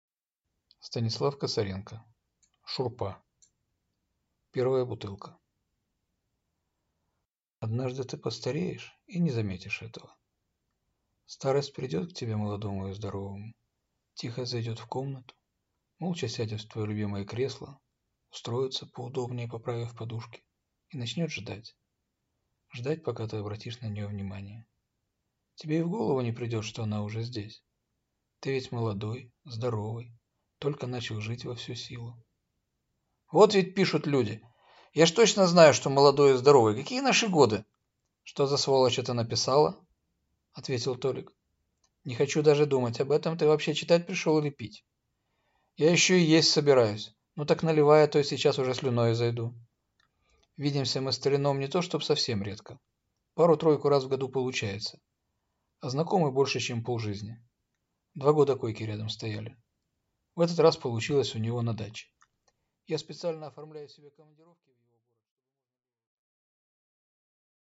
Аудиокнига Шурпа | Библиотека аудиокниг